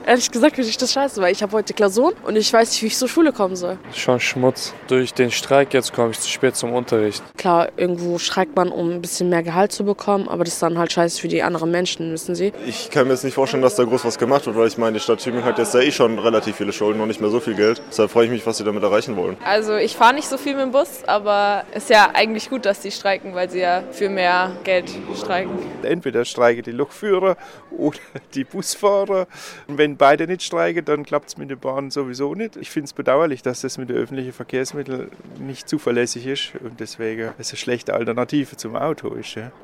Was die Menschen, die mit dem Bus fahren oder fahren wollten, von dem neuerlichen Busstreik halten - wir haben am Dienstagmorgen am zentralen Busbahnhof in Tübingen nachgefragt